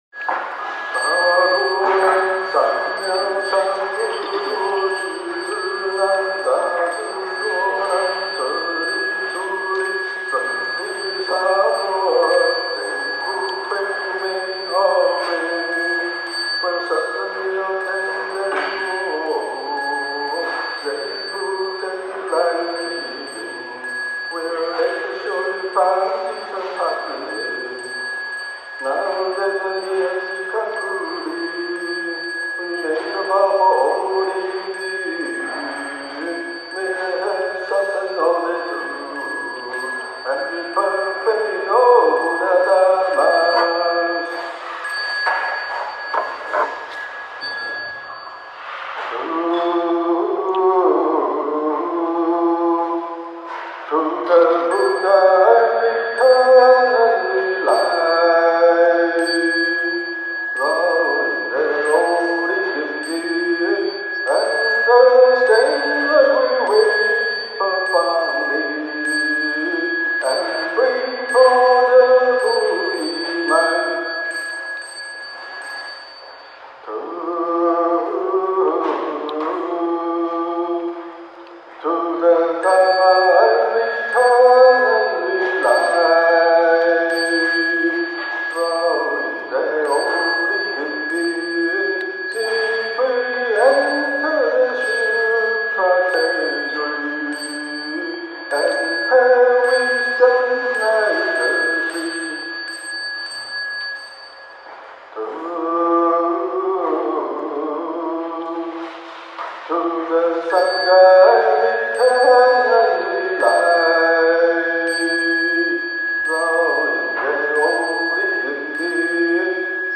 Recitation: